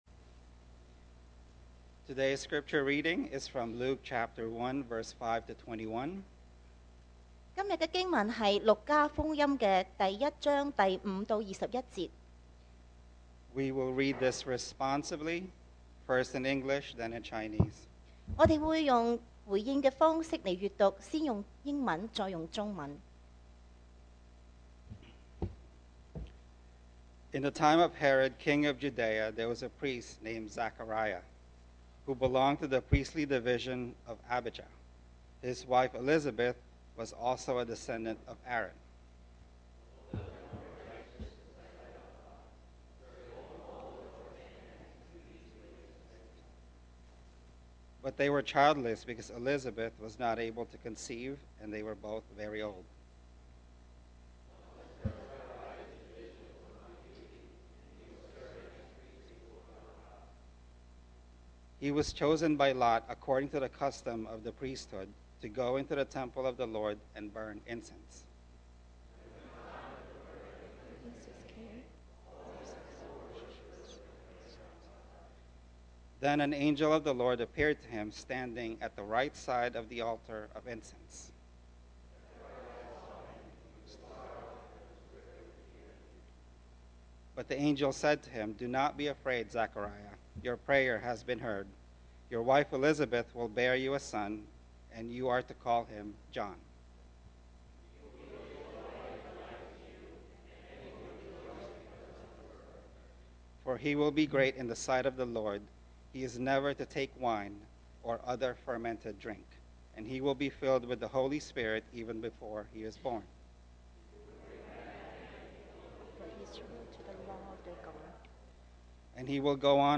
2024 sermon audios 2024年講道重溫 Passage: Luke 1:5-21 Service Type: Sunday Morning Are You Ready?